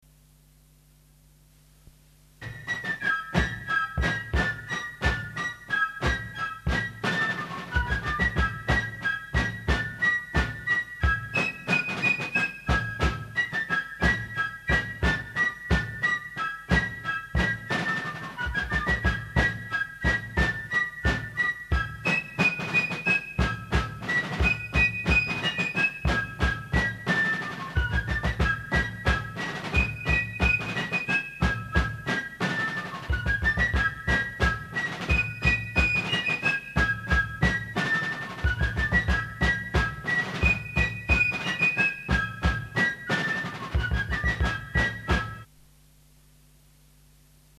De gezwinde pas telde 100 passen per minuut.